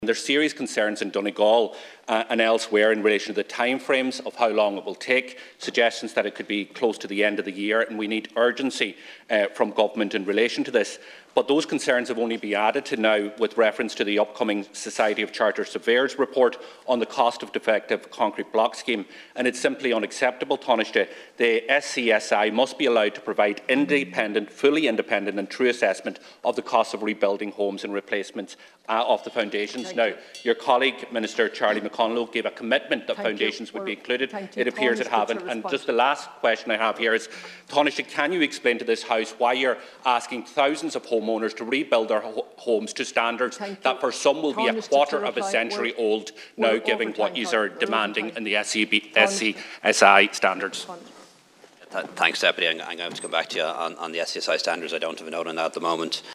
The Dail has been told that the publication this week of the Terms of Reference for the forthcoming report of the Society of Chartered Surveyors in Ireland is causing serious concerns in Donegal.
The SCSI’s figures will determine payments to homeowners under the Defective Blocks Scheme, but Deputy Pearse Doherty told Tanaiste Leo Varadkar this afternoon that there are issues with the terms of reference, particularly because there are indications that foundations will not be included.